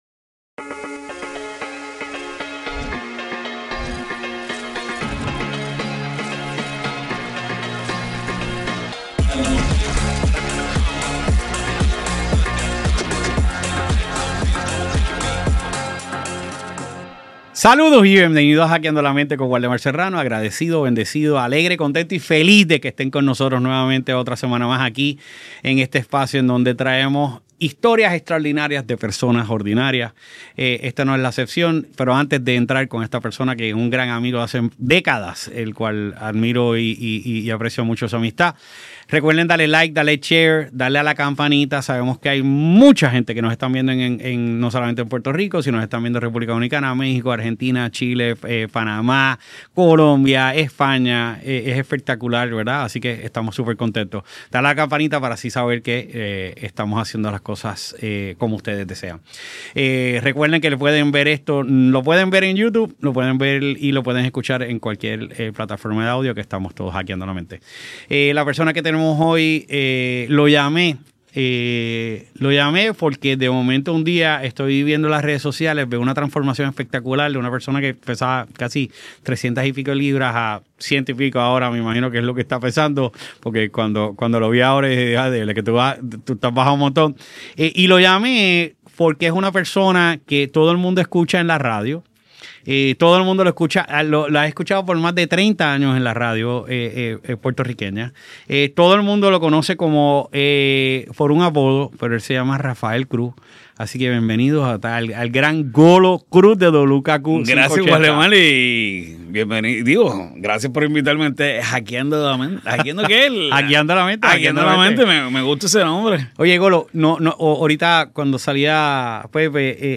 Pero esta entrevista va mucho más allá de su carrera profesional.